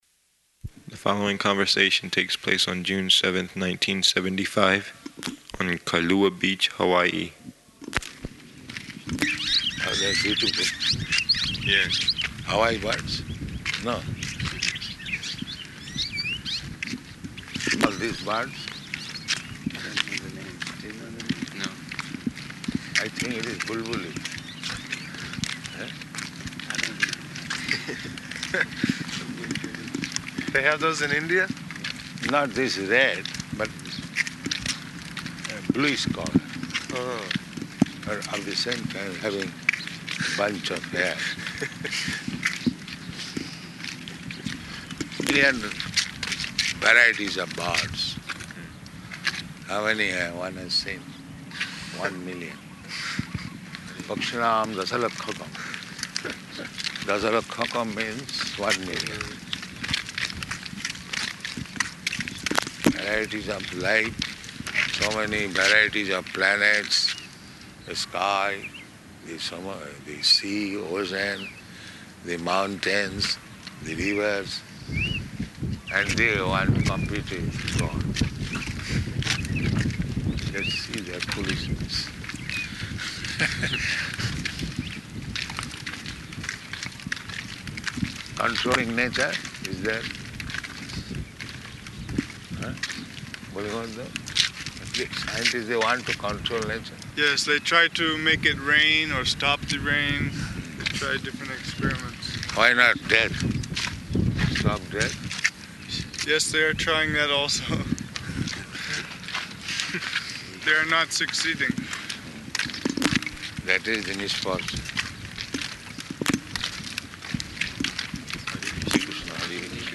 Morning Walk on Kailua Beach
Type: Walk
Location: Honolulu